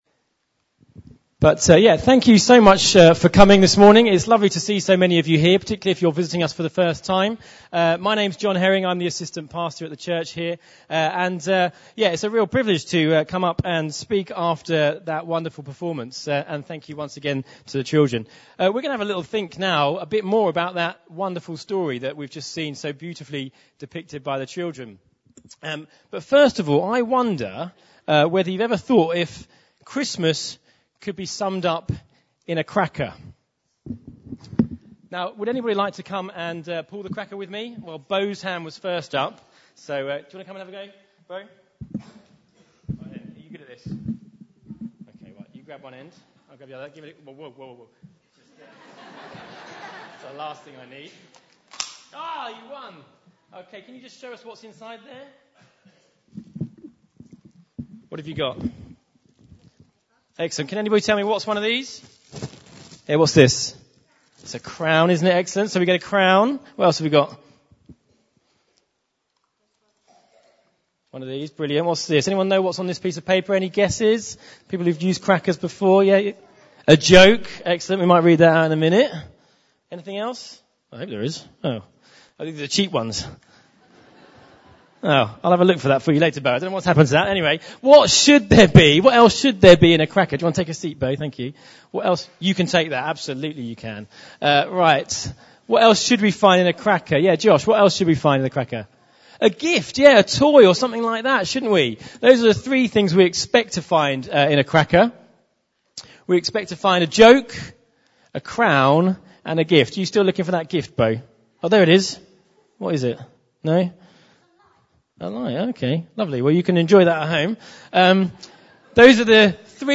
Junior Church Nativity 2017